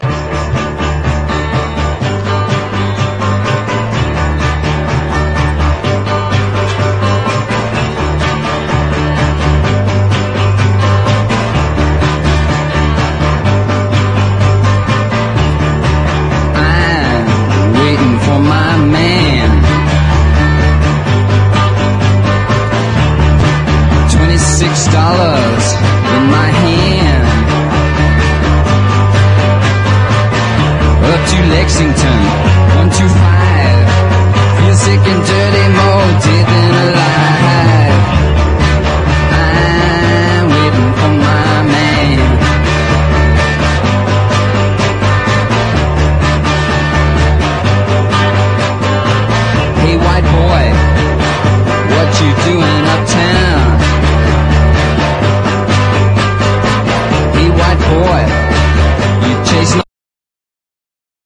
JAZZ / DANCEFLOOR / HARD BOP